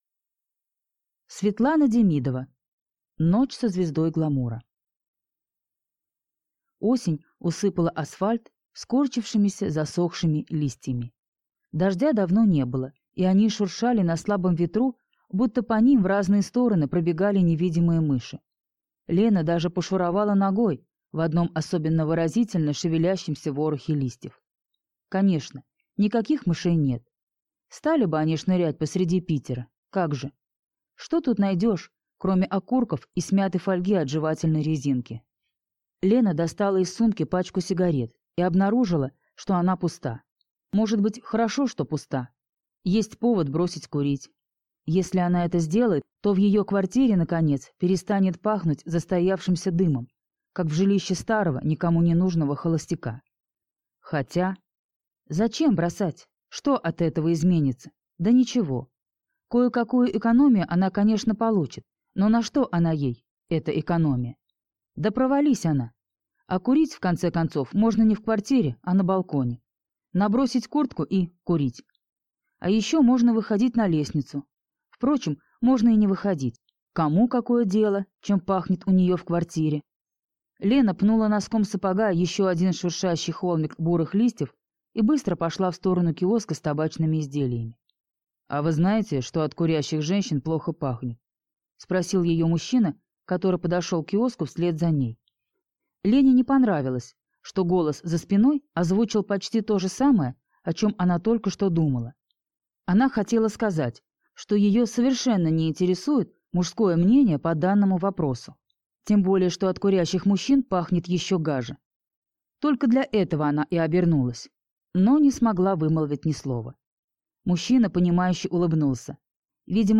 Аудиокнига Ночь со звездой гламура | Библиотека аудиокниг